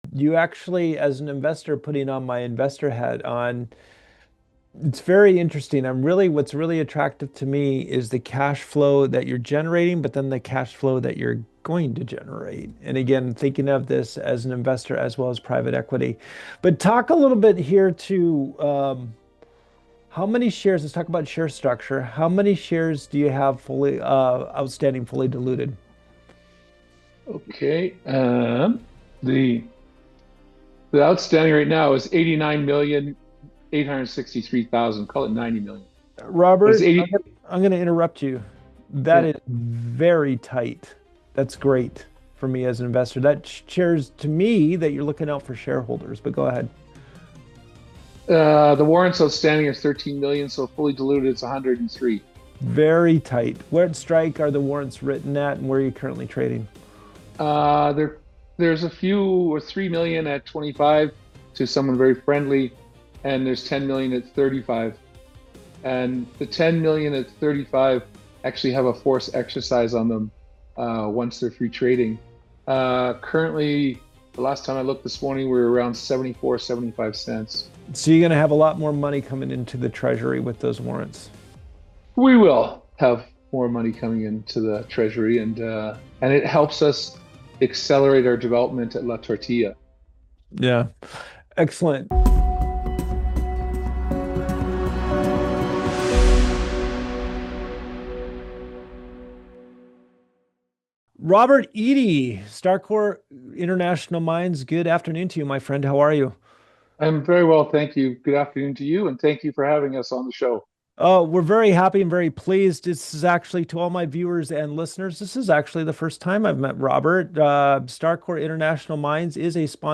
In this Natural Resource Stocks company interview